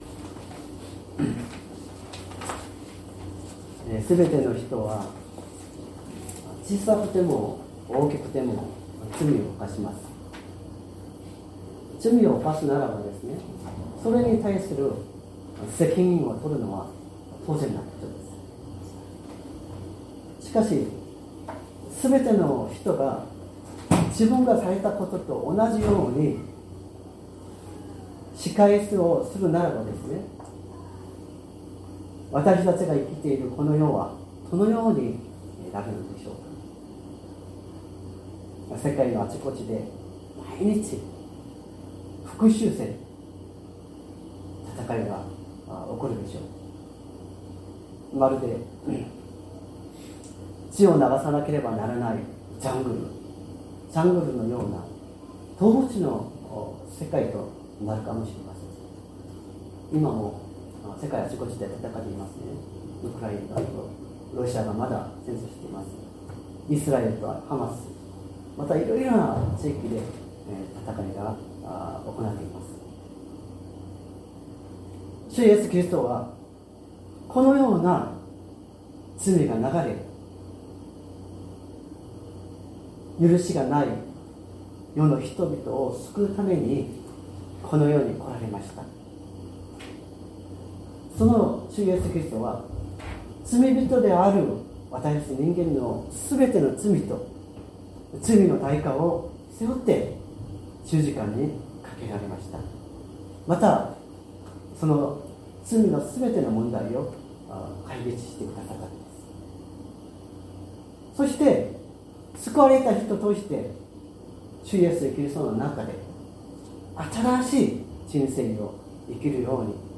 善通寺教会。説教アーカイブ 2025年03月16日朝の礼拝「逃れの町」
音声ファイル 礼拝説教を録音した音声ファイルを公開しています。